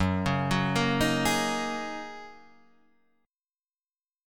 F# 6th Add 9th